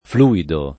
fluido [ fl 2 ido ] agg. e s. m.